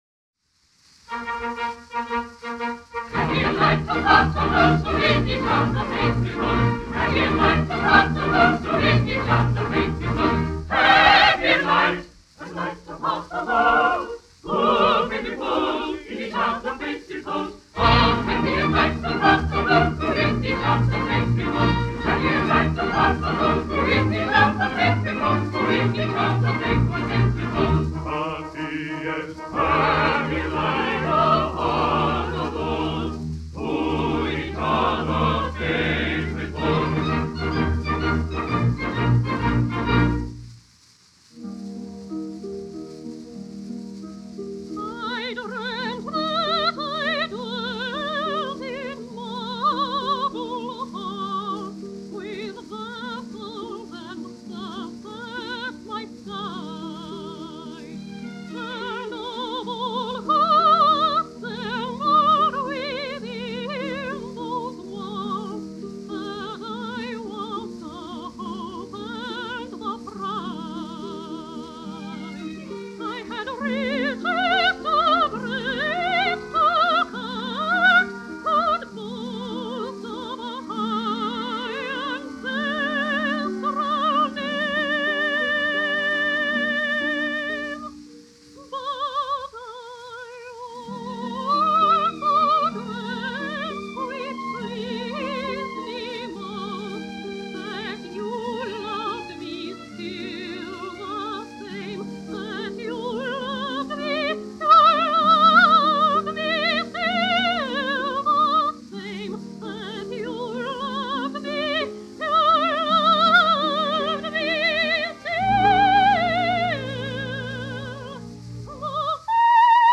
Francis Russell was a notable tenor in the fine tradition of his native Wales, Russell trained in Milan, Rome, Vienna and London, where he became principal tenor of the Royal Opera House.